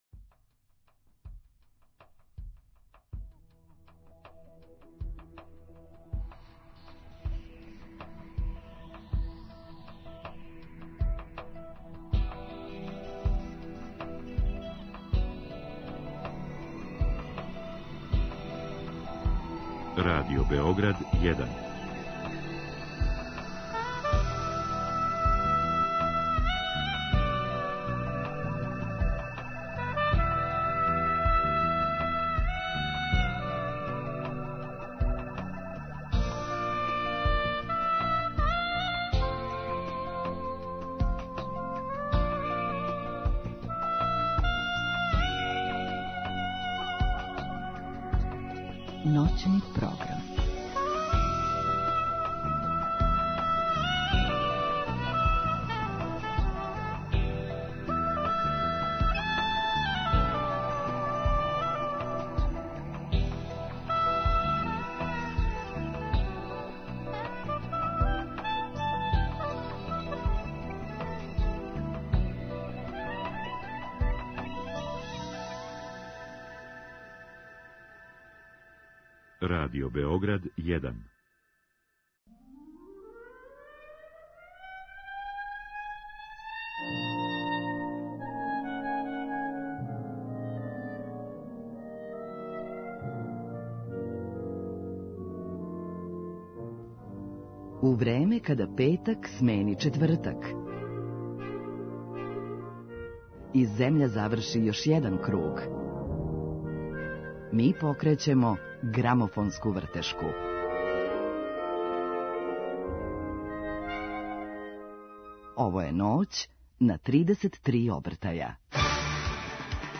Гост